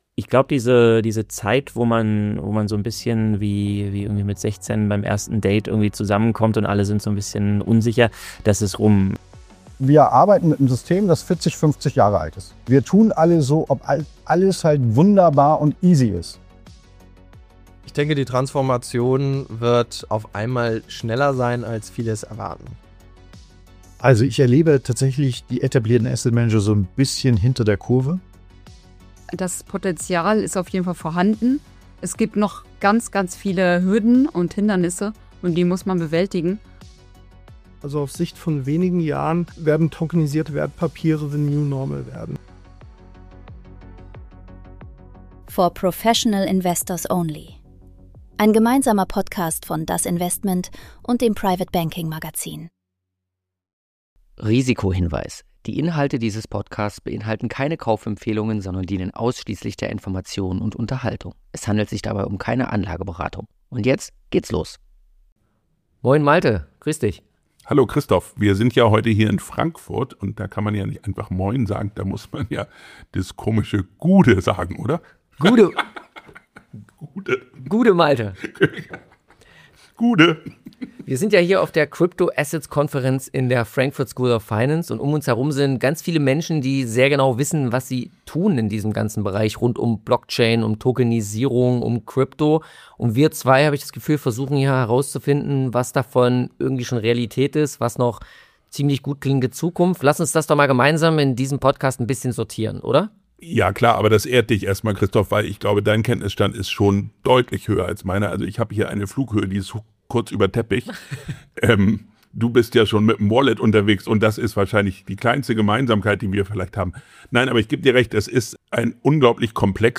Eine Konferenz-Episode für alle, die verstehen wollen, was das Finanzsystem der nächsten Dekade antreiben wird.